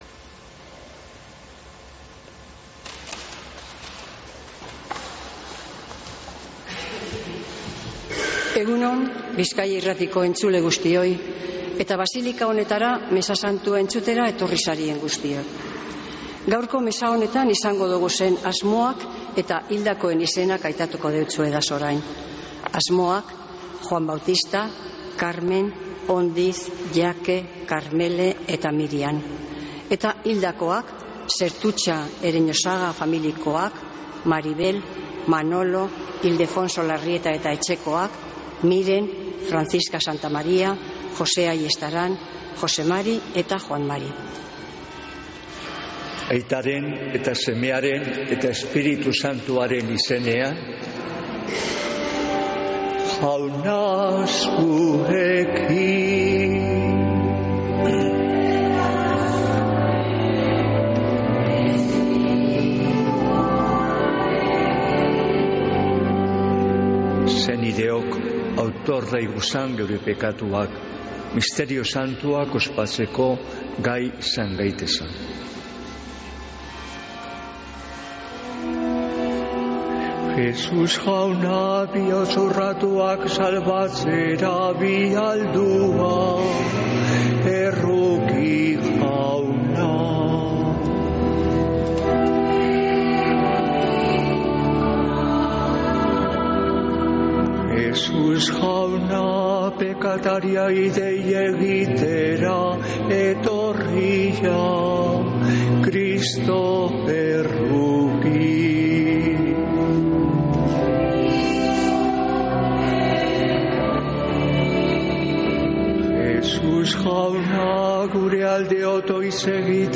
Mezea (Begoñako basilikatik) (25-09-09)